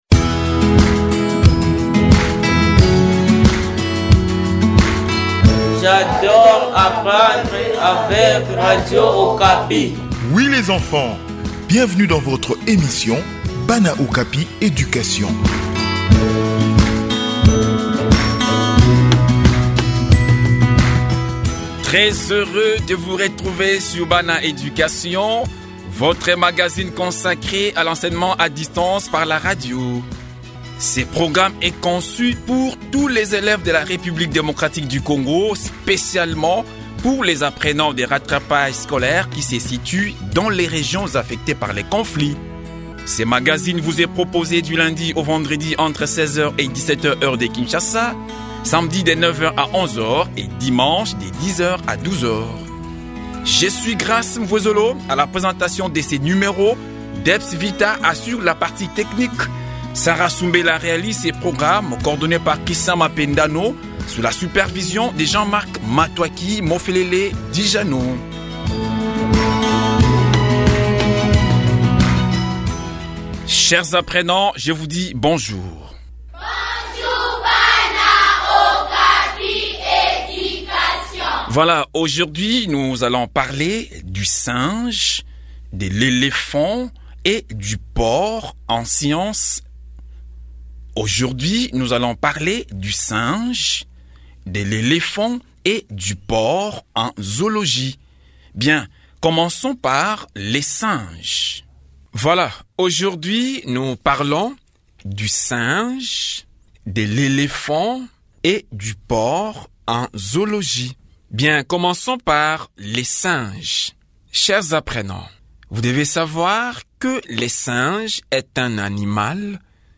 Enseignement à distance : tout savoir sur le singe, l'éléphant et le porc